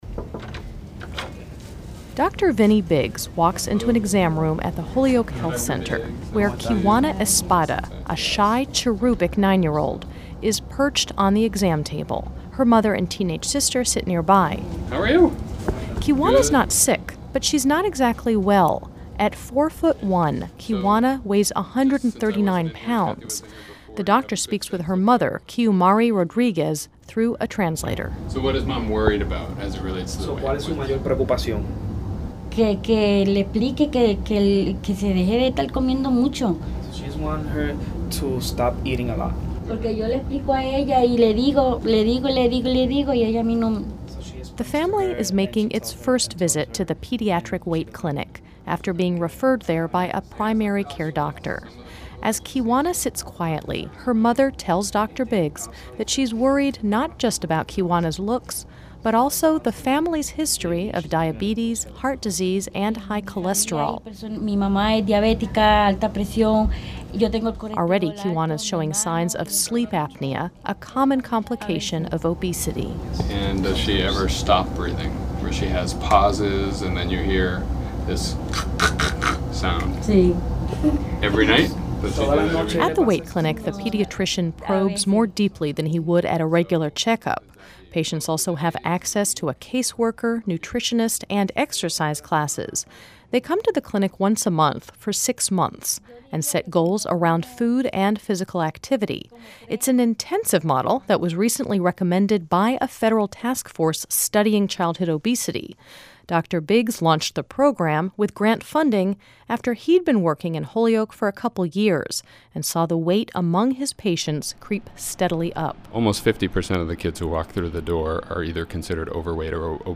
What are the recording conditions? This series first aired on WFCR in July, 2010